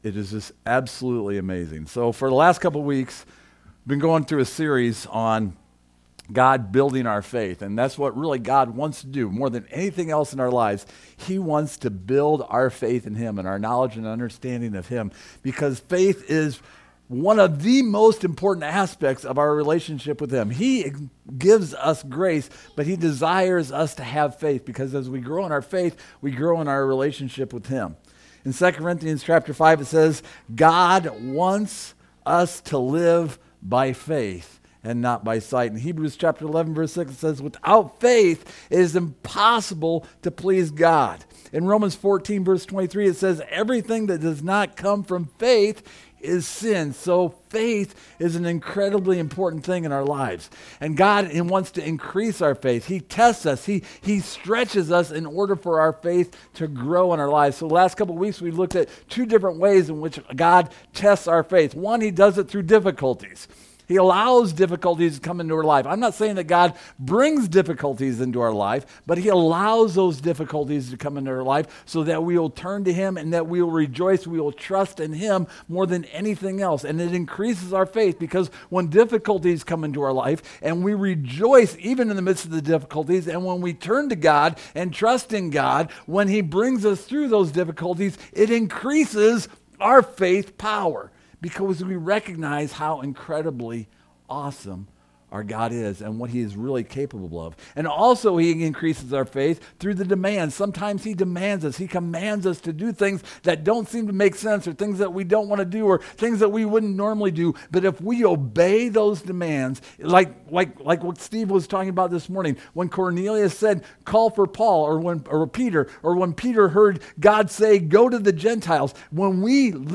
Demands and Dollars Service Type: Sunday Morning Our God uses financial highs and lows to expose the sincerity of our motives and the condition of our heart.